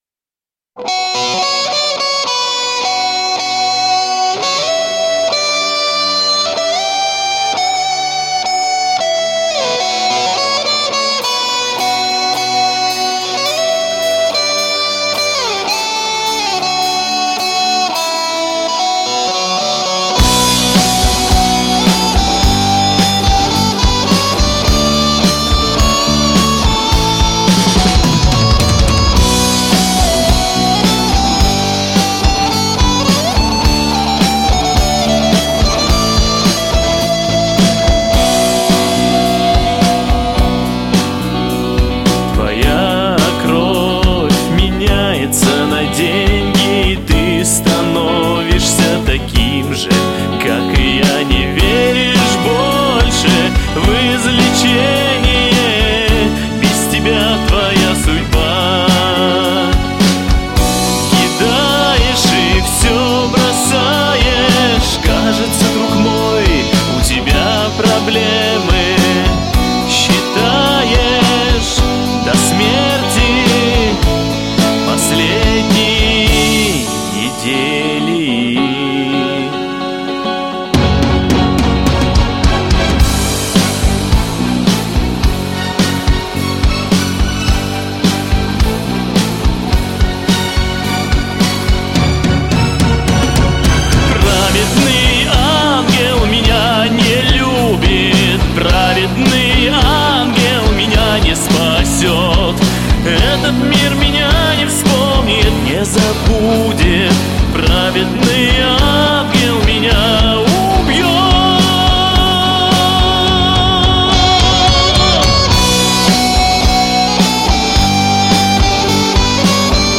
Rock
Название: Праведнй ангел Жанр: Rock Уважаемые коллеги!